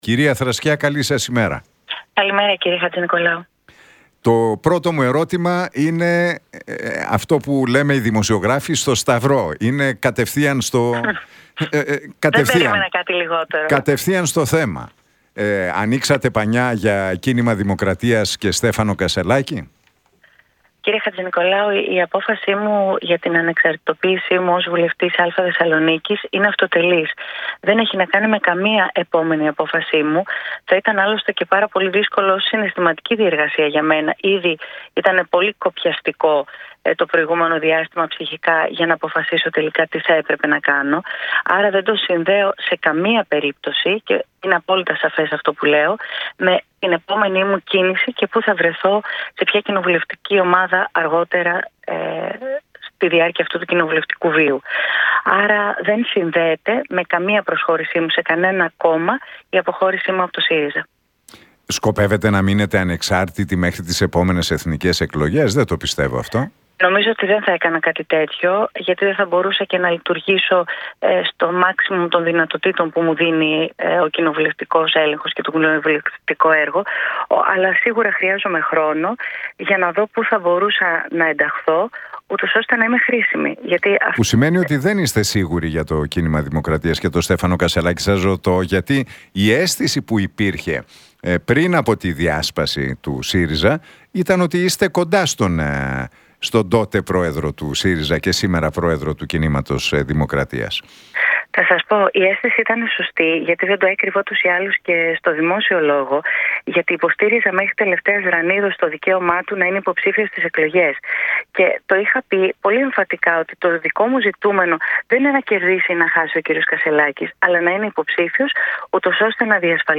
Για την αποχώρησή της από τον ΣΥΡΙΖΑ και τις επόμενες κινήσεις της μίλησε μεταξύ άλλων η Ράνια Θρακιά στον Realfm 97,8 και την εκπομπή του Νίκου Χατζηνικολάου.